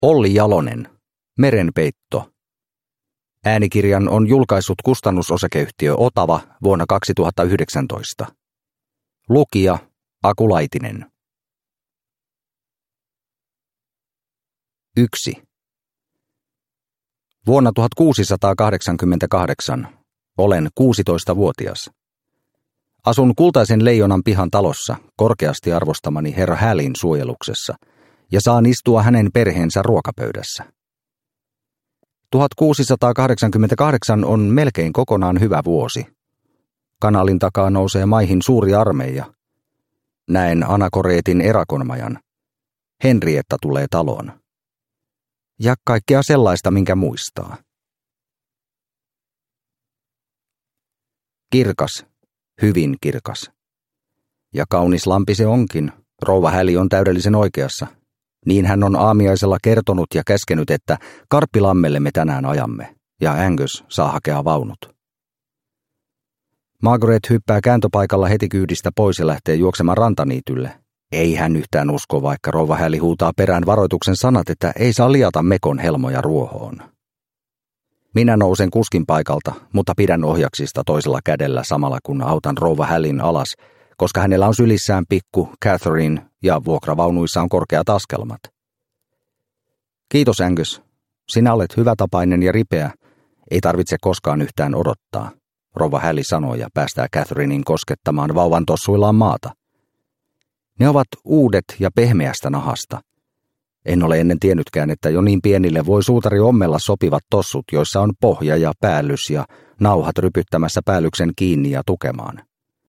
Merenpeitto – Ljudbok – Laddas ner